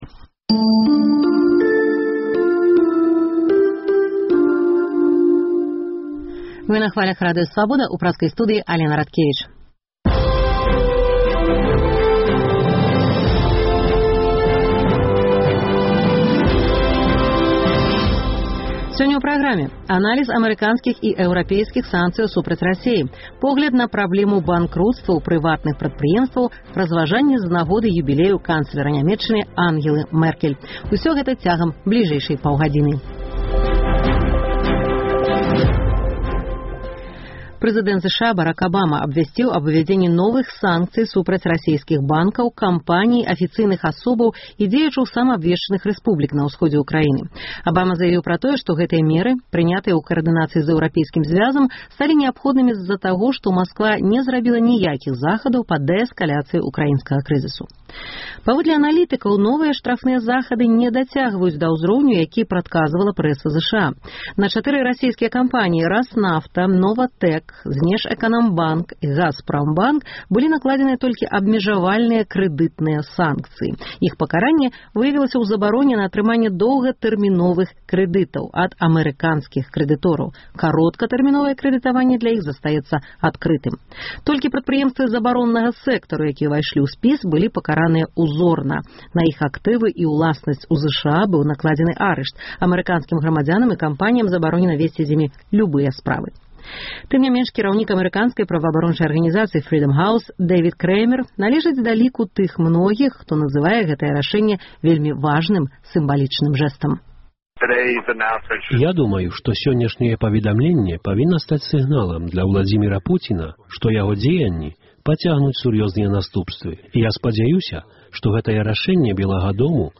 Адказы даюць амэрыканскія і беларускія аналітыкі. У праграме таксама інтэрвію з украінскім дэпутатам і пісьменьнікам Аляксандрам Брыгінцом, якога абвясьцілі ў Менску пэрсонай нон-грата, погляд на праблему банкруцтваў прыватных прадпрыемстваў і на забудовы паркавых зонаў камэрцыйным жыльлём, разважаньні з нагоды юбілею канцлера ЗША Ангелы Мэркель.